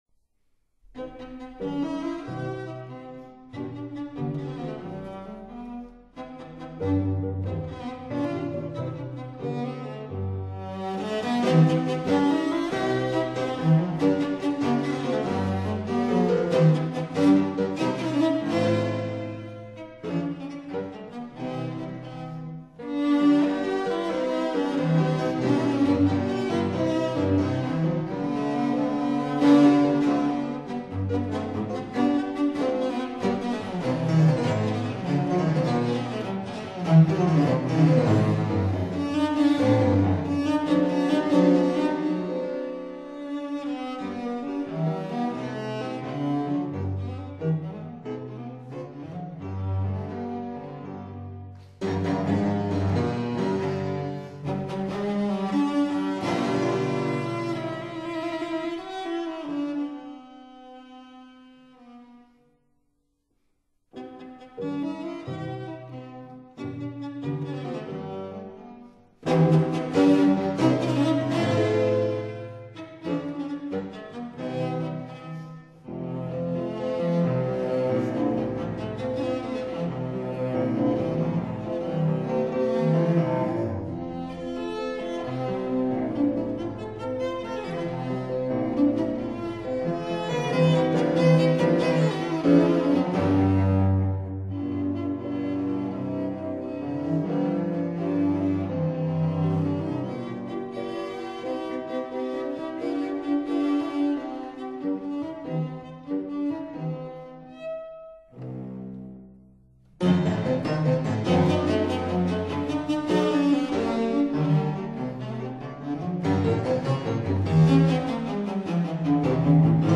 按照音樂學約定的說法, 算是古典時期的大提琴家與作曲家,